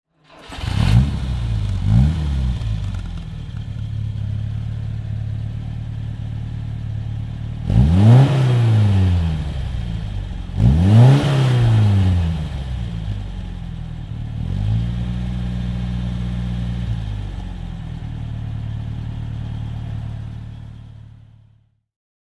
MG B (1972) - Starten und Leerlauf